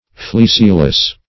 Fleeceless \Fleece"less\, a.
fleeceless.mp3